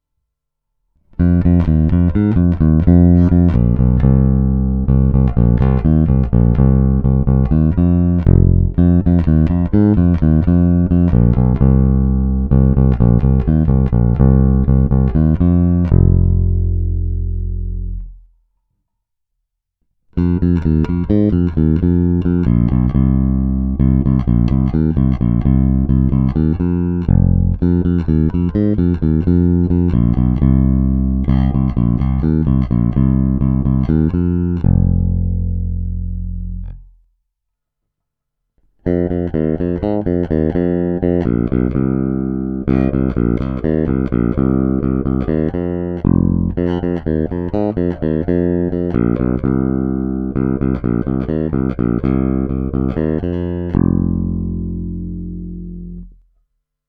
Zvuk je poměrně zvonivý, vrčí, výšek je dost.
Není-li uvedeno jinak, následující nahrávky jsou provedeny rovnou do zvukové karty s plně otevřenou tónovou clonou. Nahrávky jsou jen normalizovány, jinak ponechány bez úprav.